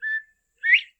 sounds_quail.ogg